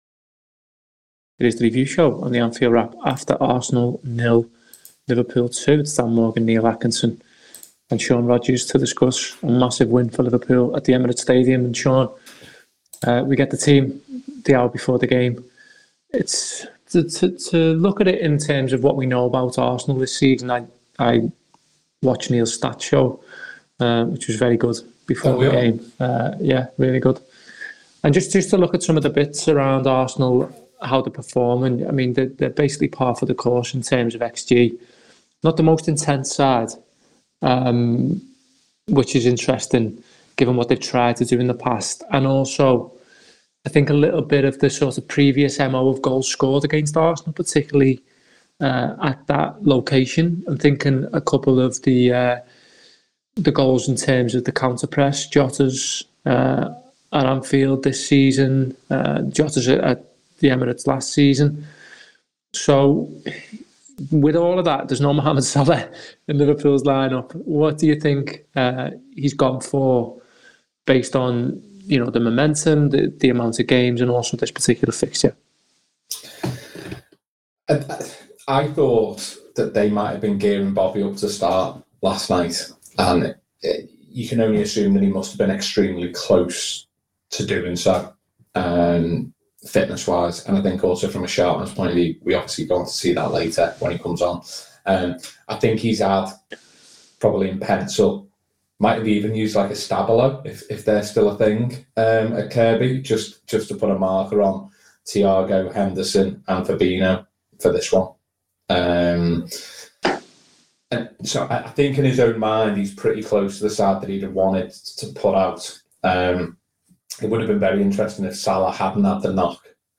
The Anfield Wrap’s review of Arsenal 0 Liverpool 2 in the Premier League at the Emirates Stadium, including a deep dive into the team selection.
Below is a clip from the show – subscribe for more Arsenal v Liverpool review…